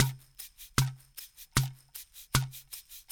AFP SHAKER-R.wav